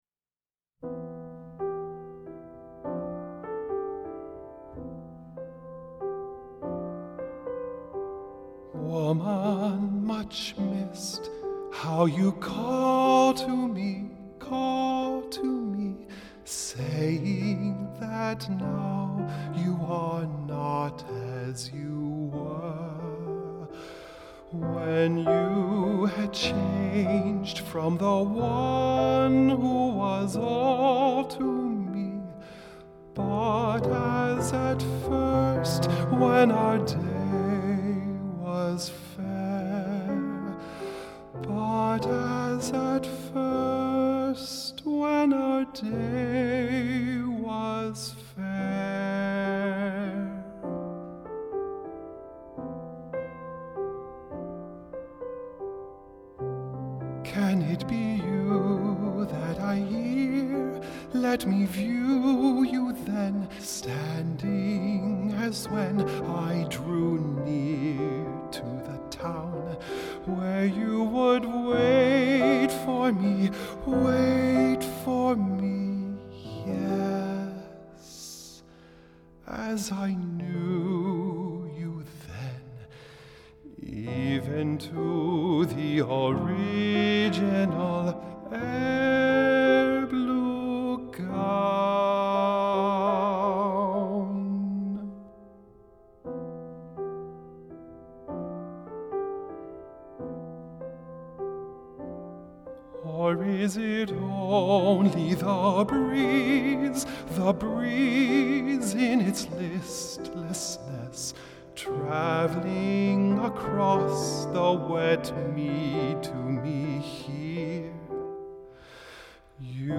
High Voice , Individual Art Songs , Medium Voice